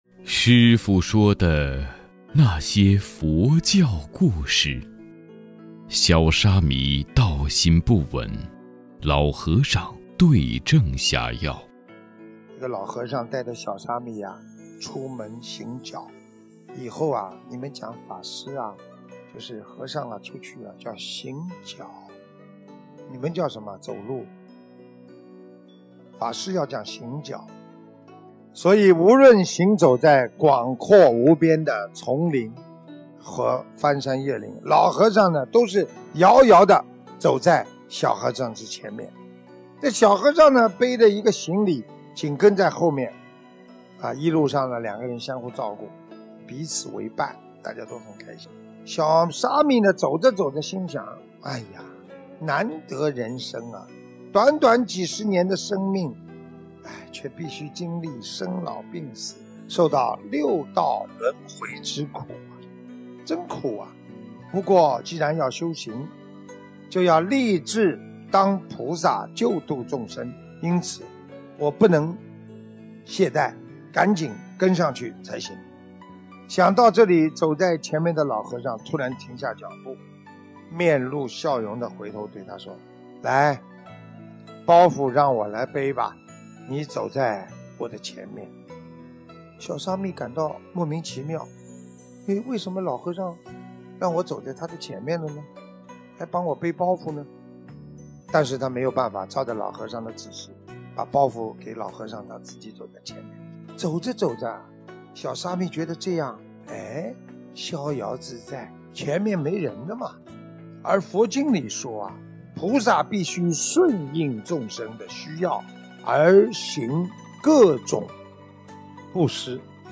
【师父原音版视频【有声大字幕【师父说的那些佛教故事】小沙弥道心不稳 老和尚对症下药.mp3